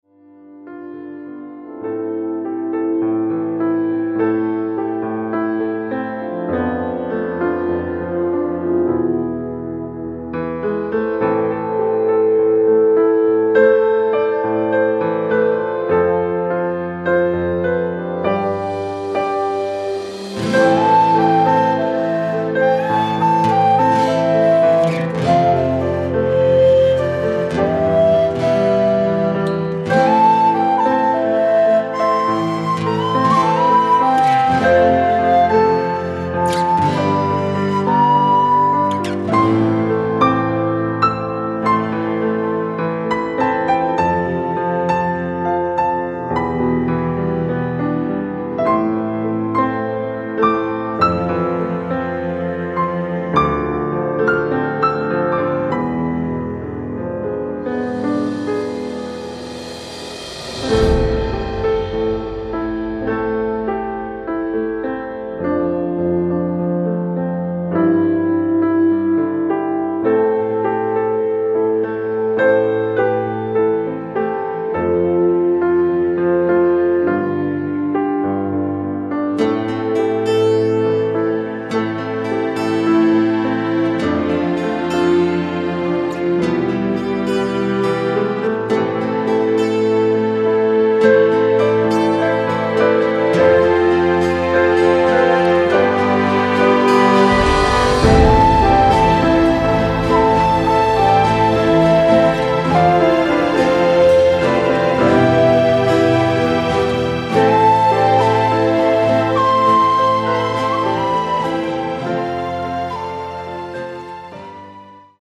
Il mastering è stato effettuato mediante sistema Creamware: la catena comprende un EQ a 32 bande, PSYQ(enhancer psicoacustico) e Optimaster (compressore multibanda)
Brano molto intenso, scritto in occasione di un evento triste. Direi che si può definire un brano in stile colonna sonora. Per il tema ho suonato il piano di Garritan Personal Orchestra (dalla quale traggo gli archi, i fiati del crescendo e le percussioni), mentre il flauto è il tin whistle di "Rare Instruments".